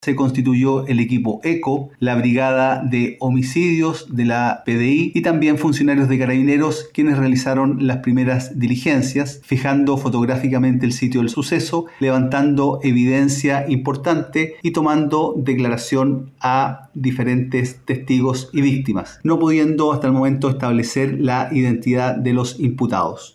Sergio Morales, fiscal de Turno y Equipo de Crimen Organizado, detalló los trabajos realizados por los equipos de investigación, notificando que no se ha logrado identificar a los responsables.